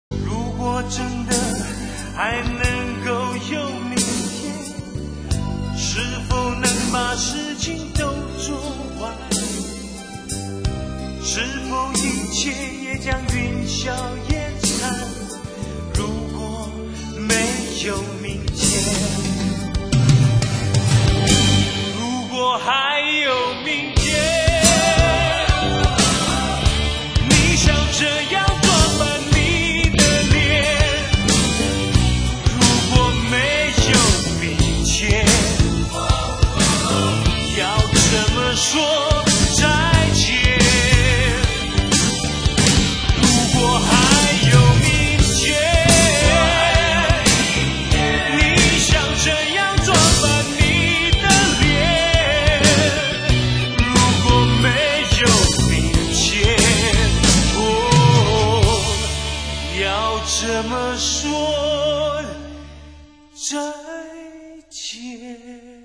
專輯類別：國語流行、絕版重現